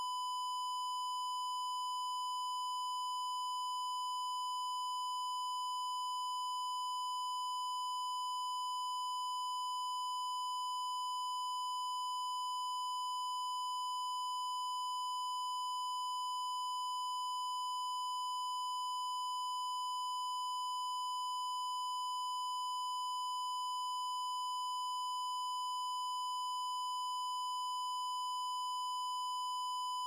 1k.wav